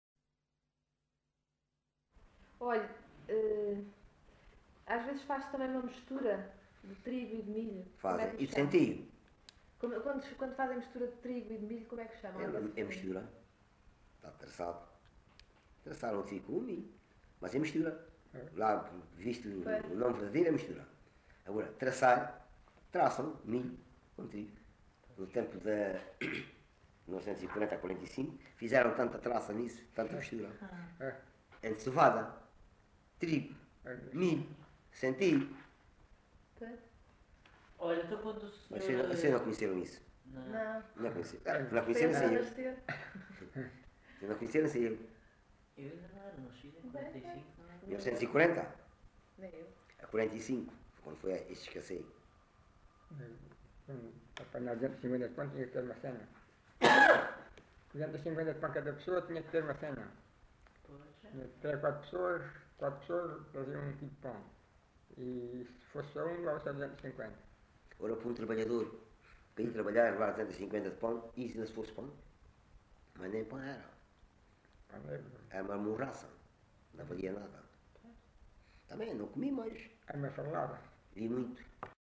Alte, excerto 29
LocalidadeAlte (Loulé, Faro)